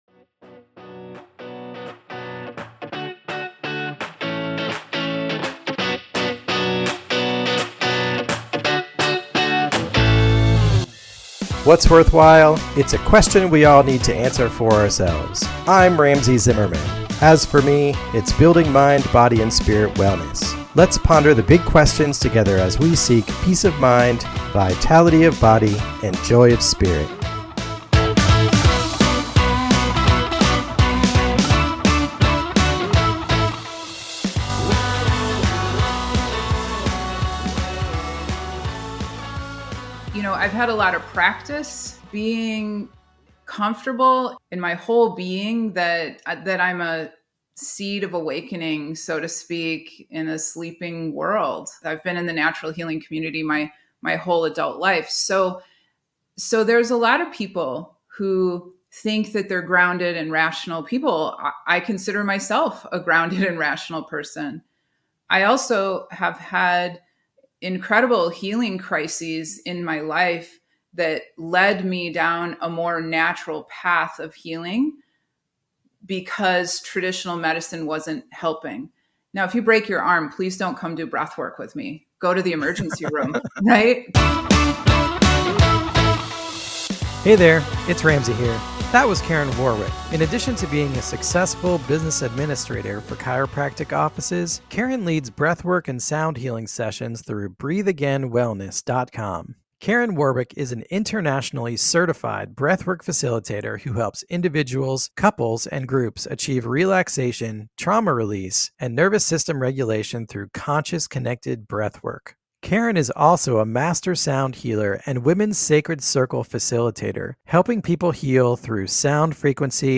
This discussion gives a thorough overview of how conscious connected breathwork can be used to release tension and trauma, as well as how sound therapy can clear the chakra energy centers of the body.